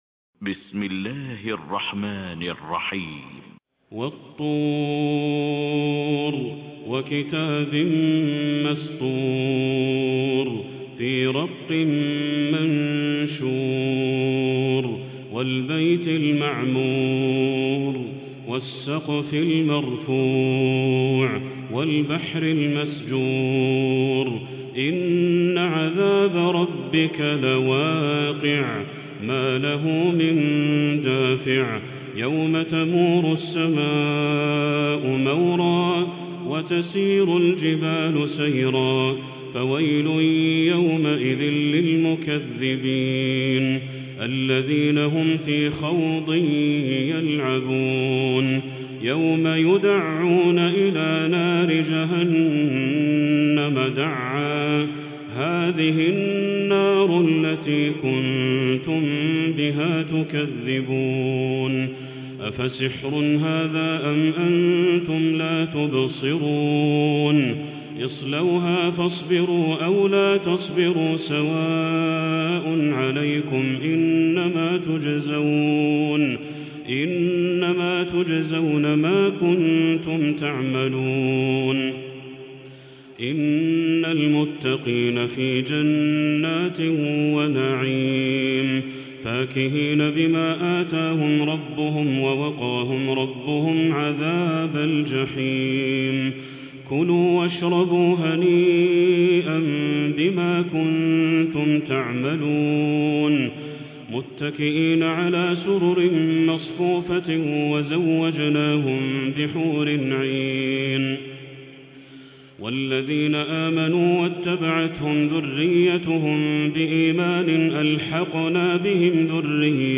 Quran recitations
Tarawih prayer from the holy Mosque